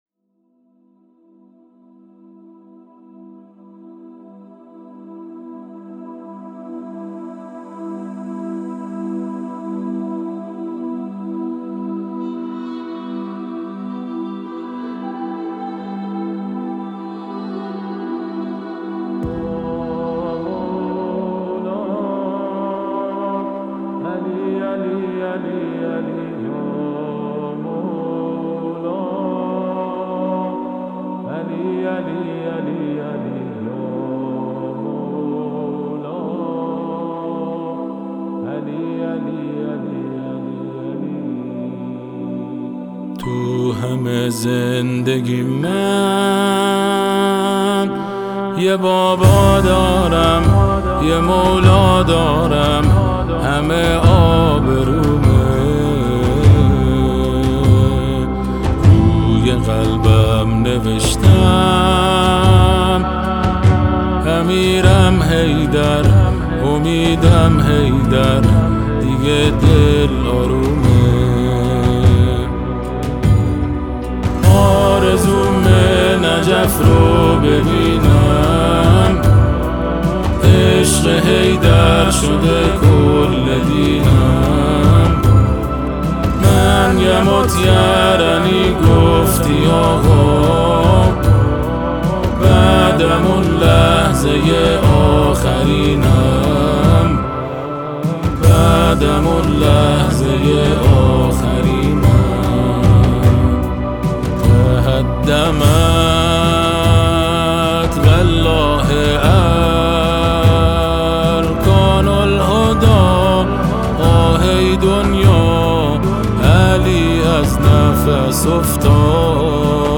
نواهای مذهبی و آئینی